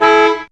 Index of /m8-backup/M8/Samples/Fairlight CMI/IIe/27Effects4
CarHorn.wav